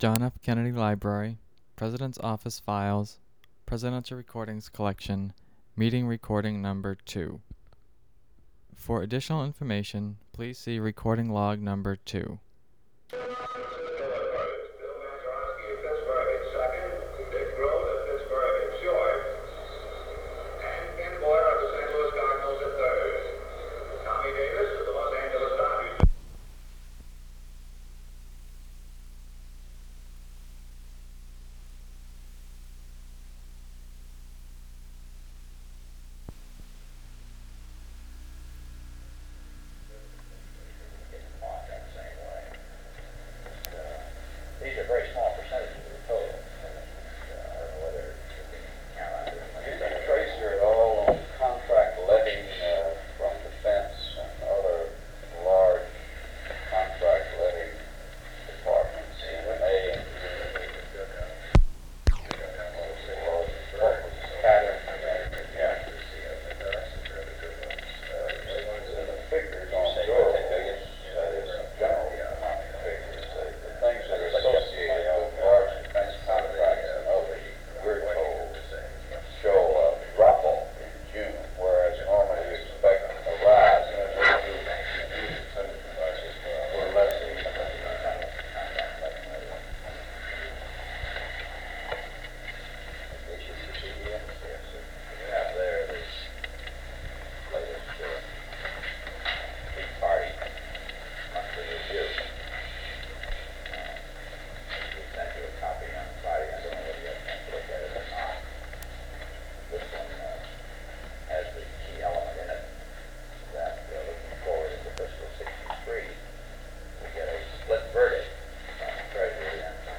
Secret White House Tapes | John F. Kennedy Presidency Meeting on the Economy and the Budget Rewind 10 seconds Play/Pause Fast-forward 10 seconds 0:00 Download audio Previous Meetings: Tape 121/A57.